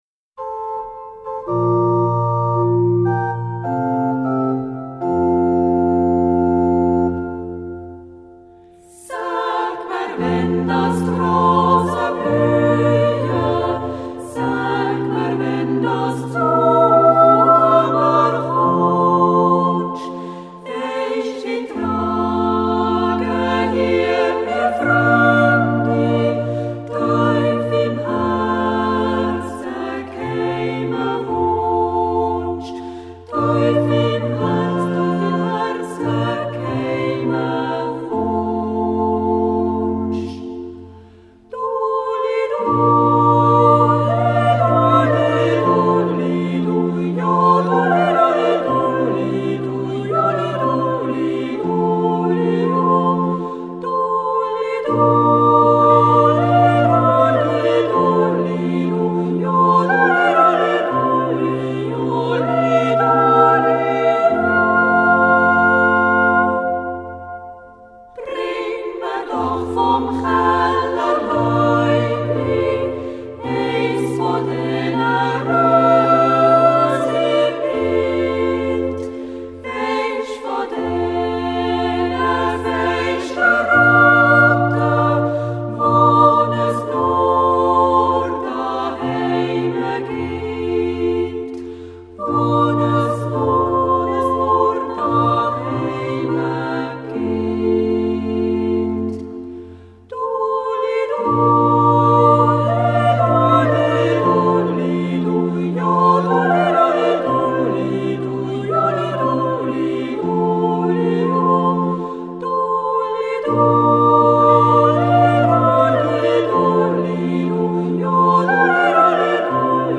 jodel
alphorn
organ.